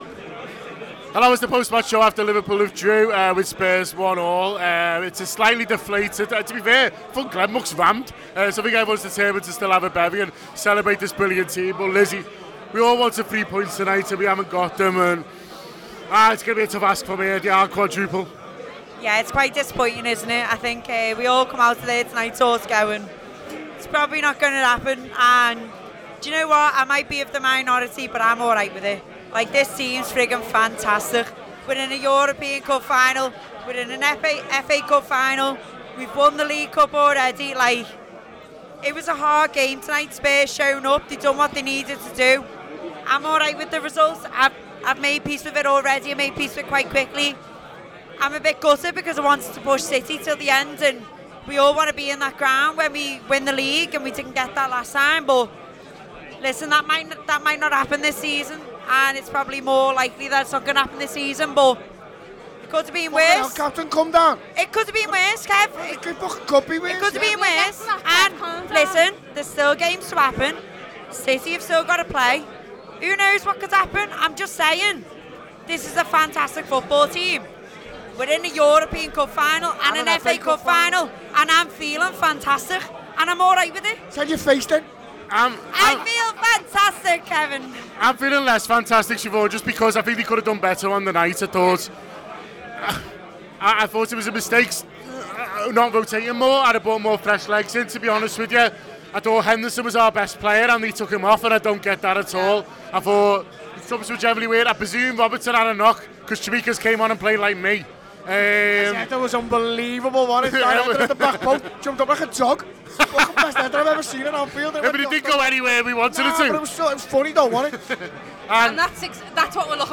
The Anfield Wrap’s post-match reaction podcast after Liverpool 1 Tottenham Hotspur 1 in the Premier League at Anfield.